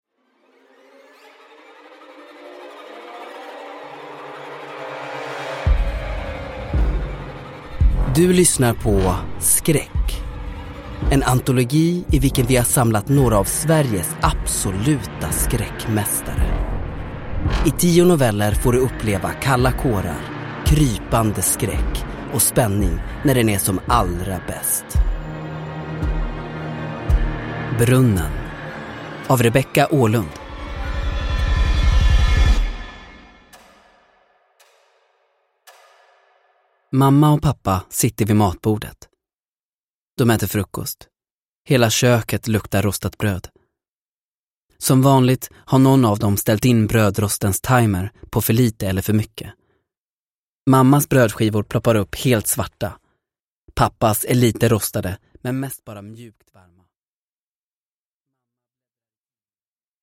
Skräck - Brunnen – Ljudbok – Laddas ner